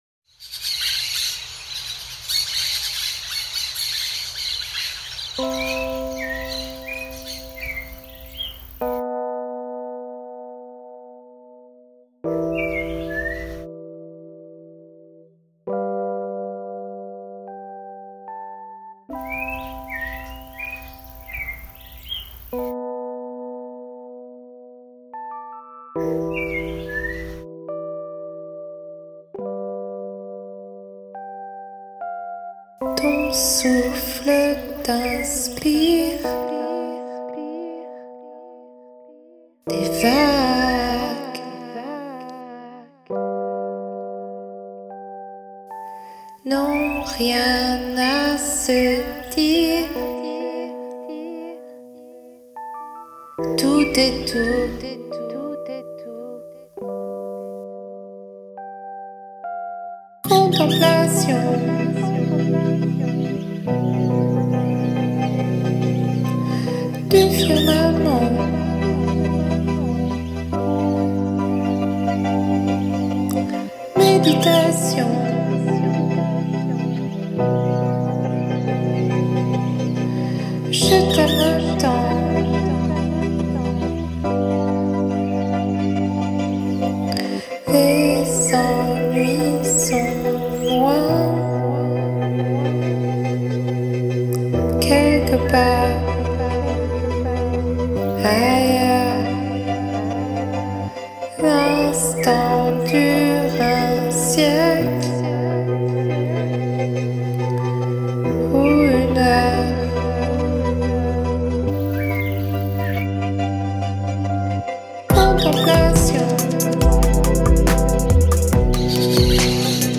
Disco, electro pop, r n b freedom songs